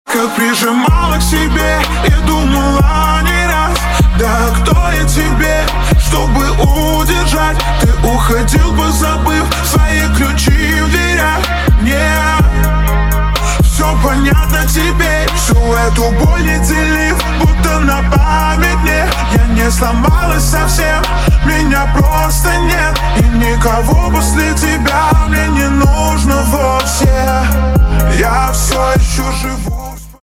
• Качество: 320, Stereo
remix
грустные
Chill Trap